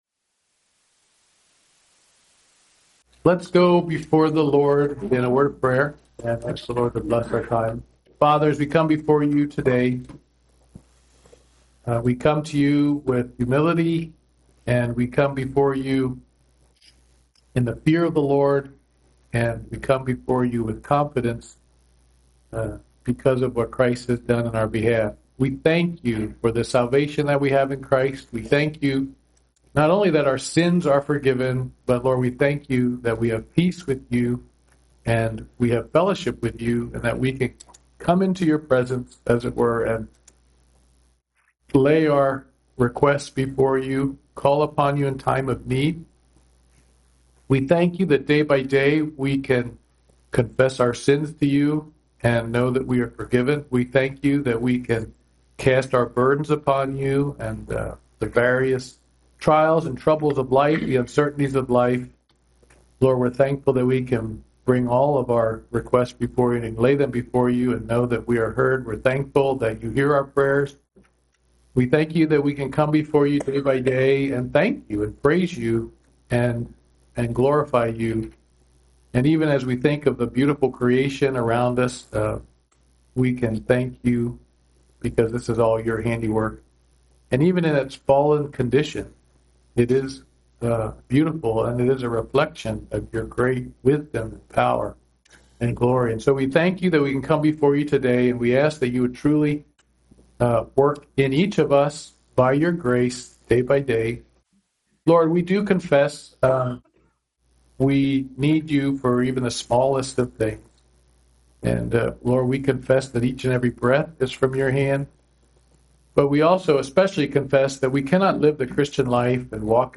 Ecclesiastes 9:1-6 Service Type: Wednesday Morning Bible Study « Intro to Mark